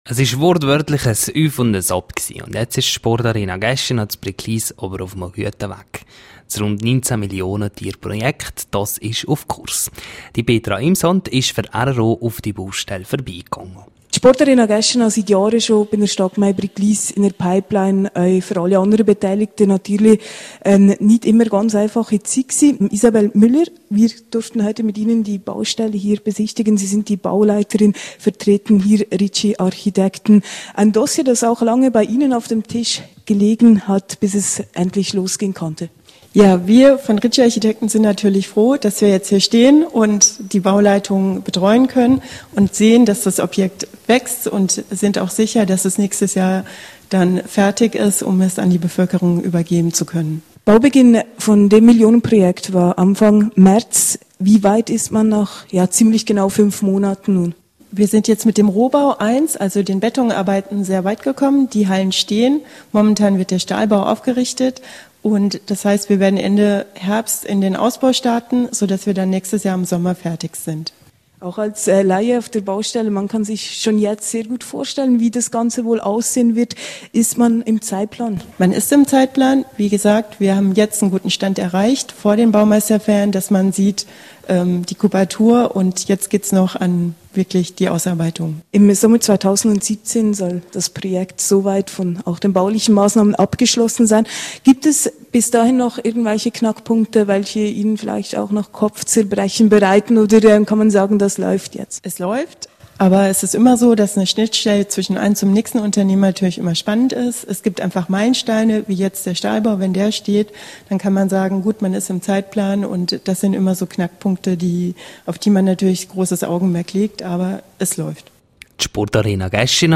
rro besichtigt am Mittwoch die Baustelle der Sportarena Geschina.